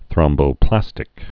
(thrŏmbō-plăstĭk)